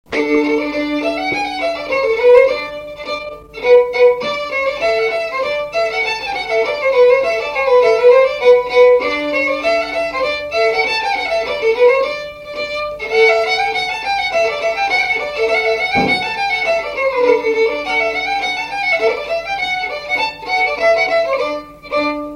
Résumé instrumental
danse : pas d'été
Pièce musicale inédite